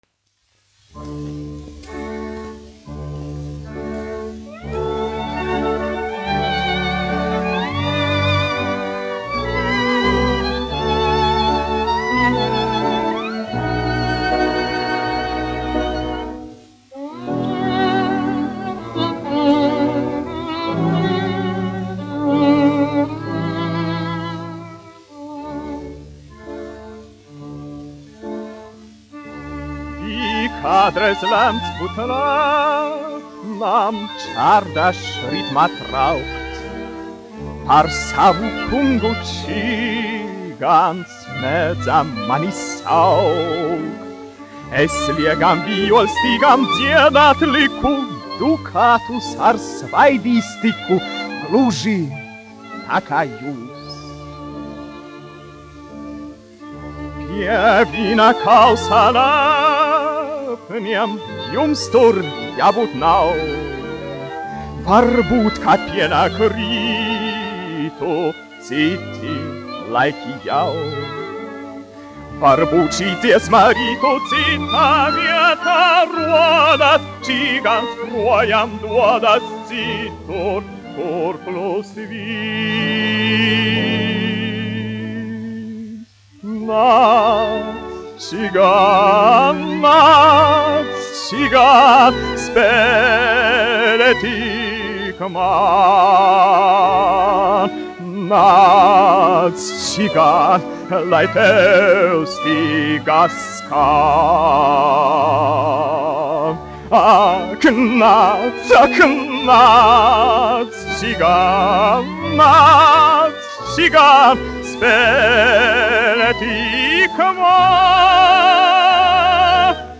1 skpl. : analogs, 78 apgr/min, mono ; 25 cm
Operetes--Fragmenti
Latvijas vēsturiskie šellaka skaņuplašu ieraksti (Kolekcija)